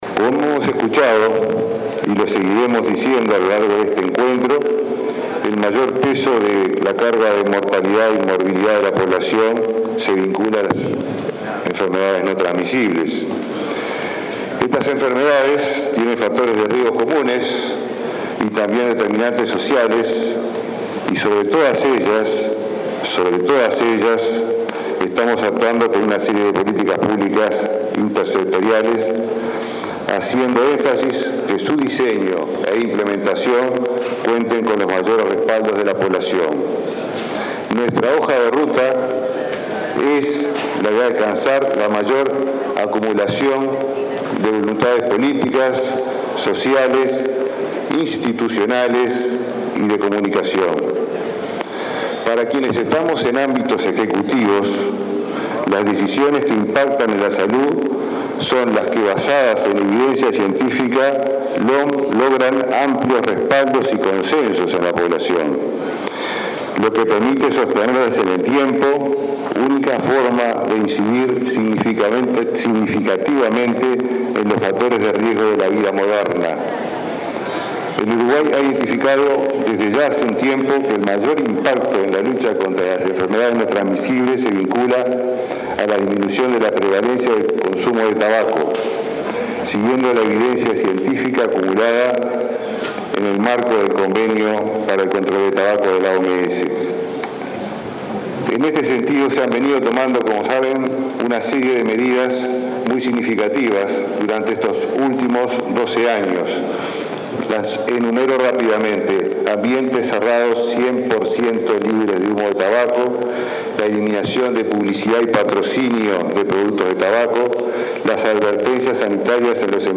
Uruguay mejoró sus indicadores de salud a partir de políticas intersectoriales, con tasas de mortalidad infantil y materna en tendencia a la baja, destacó el ministro Jorge Basso, en su alocución durante la primera jornada de la Conferencia Mundial sobre Enfermedades No Transmisibles. Una de esas estrategias permitió que la prevalencia de fumadores en mayores de 15 años disminuyera de 35 % en 2006 a 21,6 % en la actualidad.